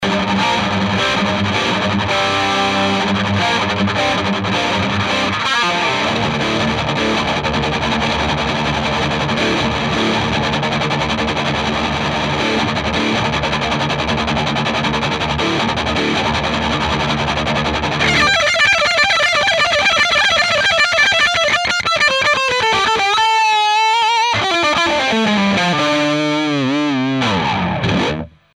Сделал, преамп, типо, Богнер и поменял на гитаре ТВ-4 на ТВ-5...
вот как это звучит (записано SM-57):